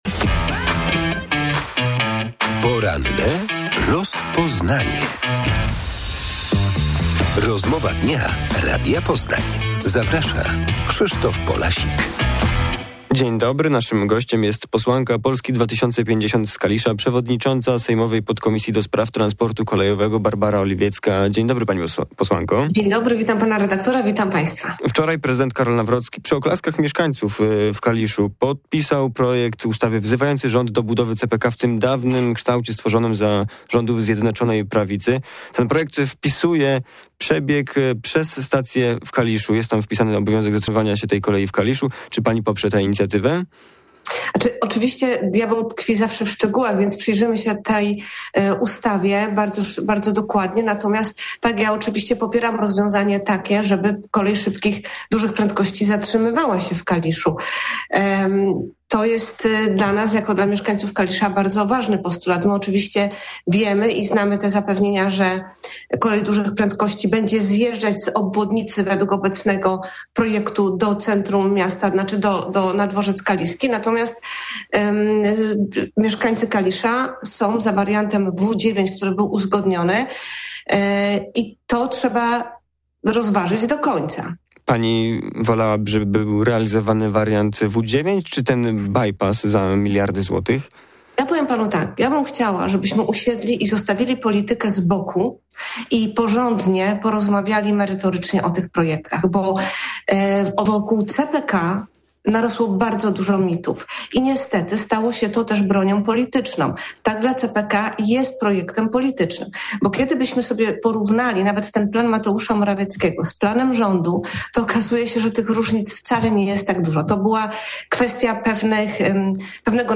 Wczoraj w Kaliszu prezydent Karol Nawrocki podpisał projekt prezydenckiej ustawy przewidującej realizację CPK w dawnym wariancie. Gościem rozmowy jest kaliska posłanka Polski 2050 Barbara Oliwiecka.